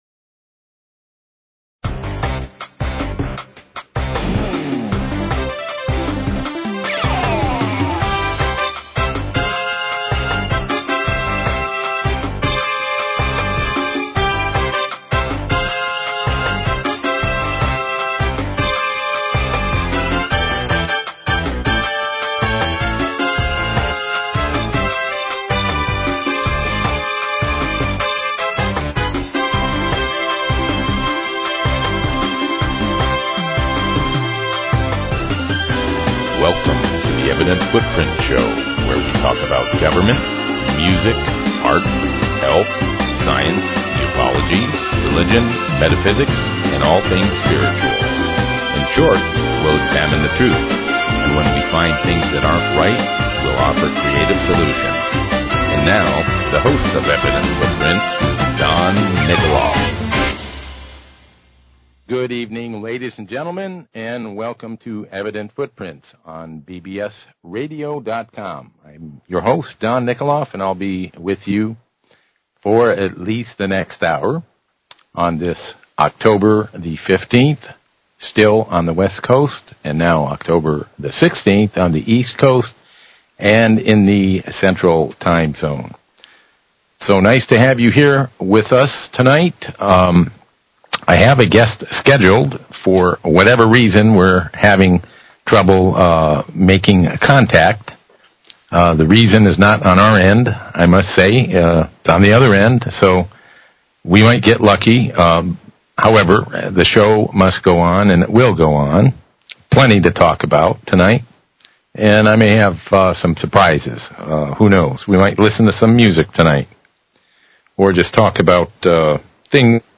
Talk Show Episode, Audio Podcast, Evident_Footprints and Courtesy of BBS Radio on , show guests , about , categorized as
Environmental Scientist, Health Consultant, Herbalist